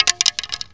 racket_fall02.mp3